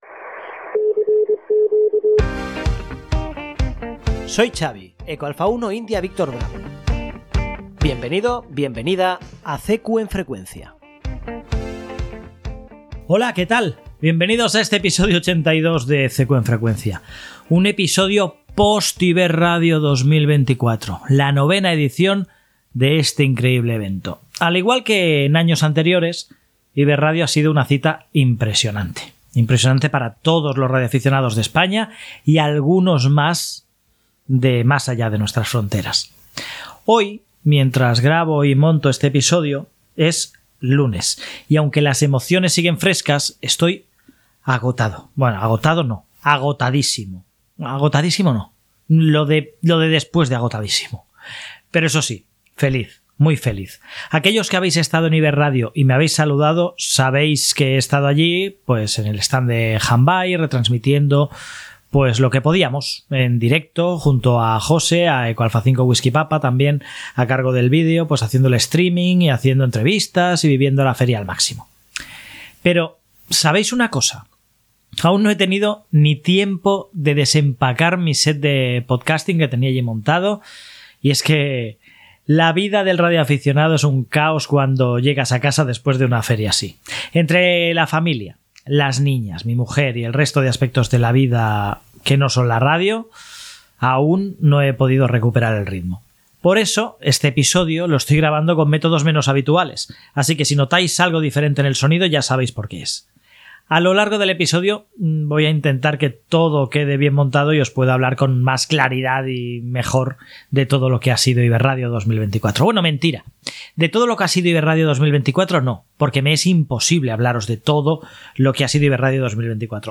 En este episodio 82, os traigo todas las emociones y momentos destacados de la novena edición de IberRadio, celebrada este fin de semana en Ávila. Compartiré mis impresiones personales sobre la feria, desde la perspectiva de los expositores hasta los grandes debates sobre los equipos más innovadores, como el nuevo ICOM 7760.